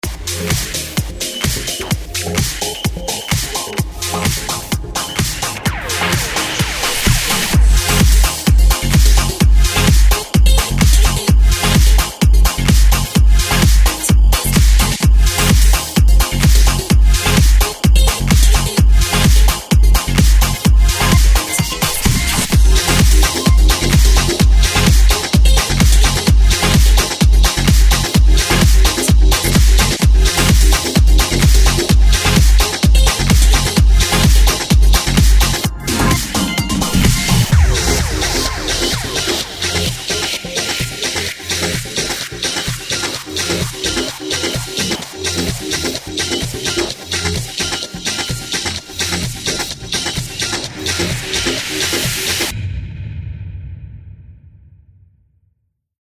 soiree - fetes - nuit - danse - club